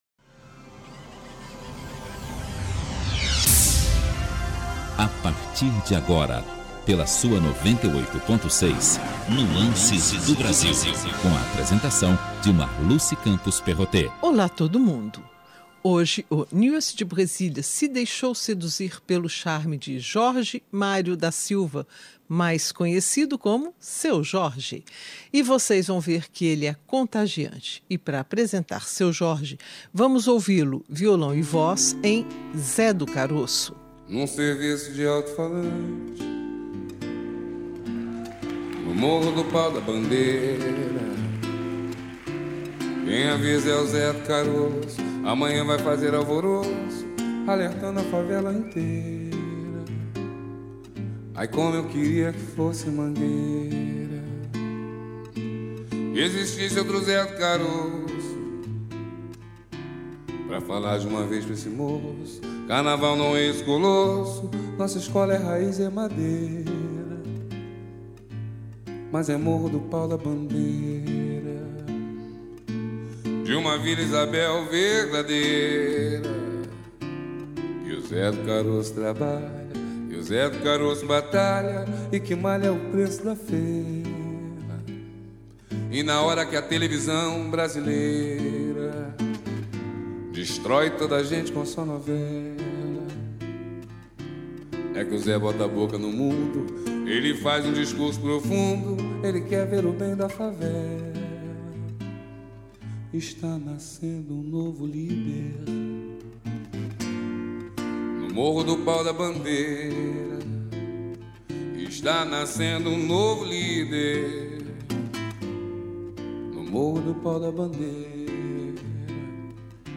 «Nuances do Brasil » Encontre o seu artista preferido, conheça as novas promessas e nuances da MPB (Música Popular Brasileira).